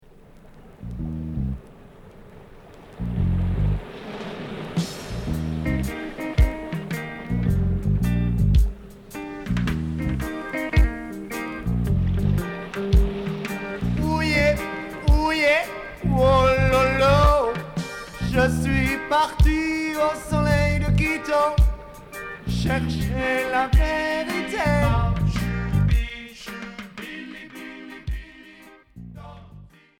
Rock swing et reggae